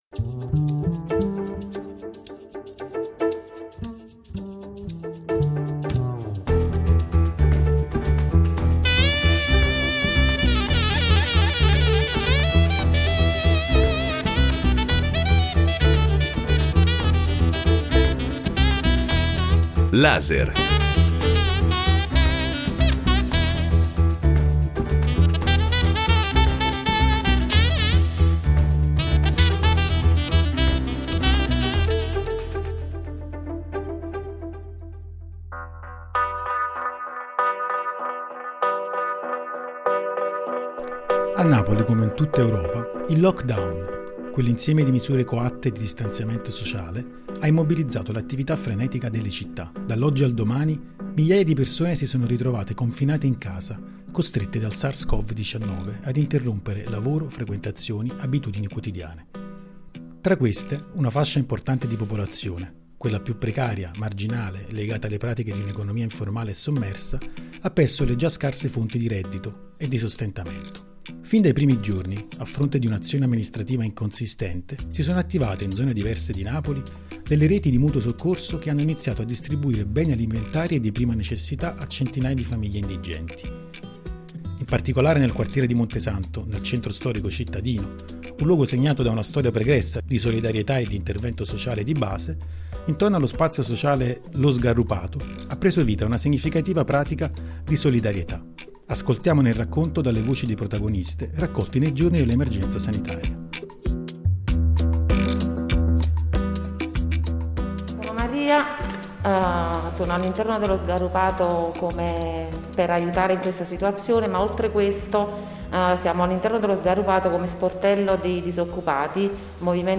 "Lockdown napoletano" racconta, attraverso le voci e i racconti dei protagonisti, le diverse condizioni di vita e le pratiche di adattamento e resistenza all’epidemia.